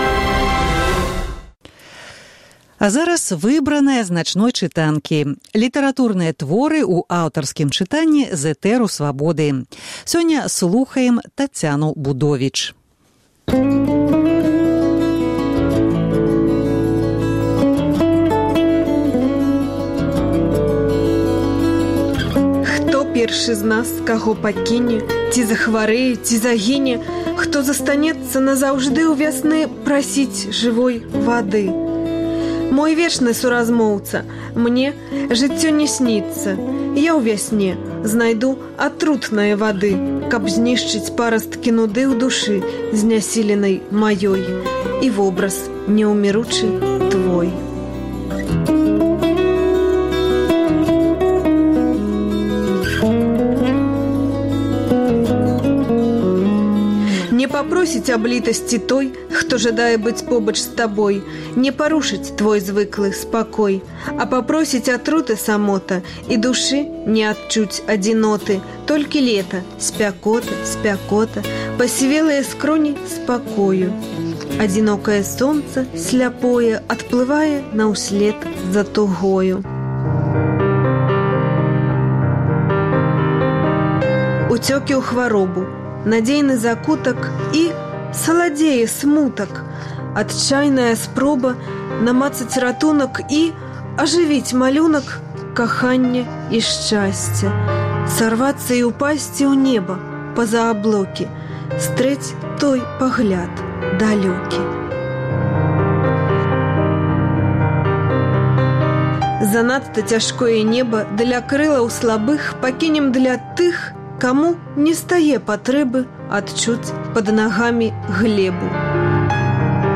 Улетку мы паўтараем перадачы з архіву Свабоды. У «Начной чытанцы» — 100 сучасных аўтараў чыталі свае творы на Свабодзе.